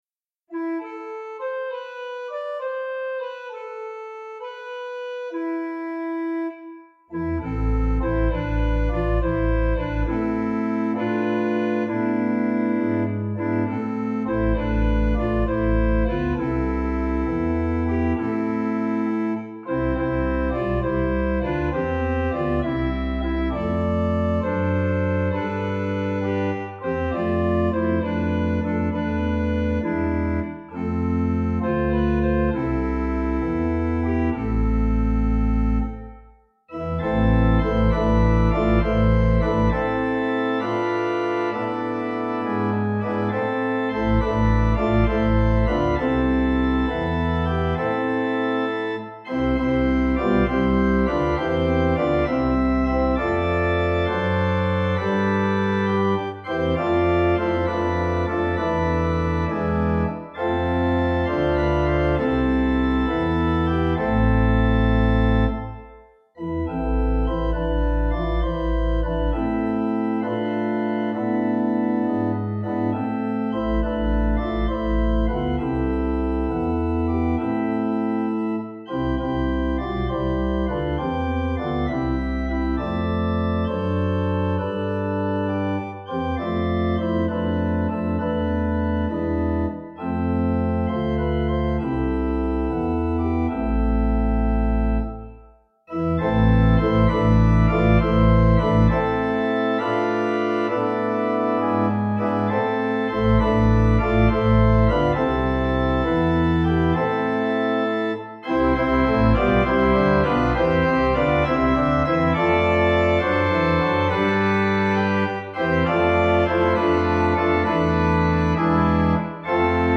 Traditional Basque Carol
Organ: Little Waldingfield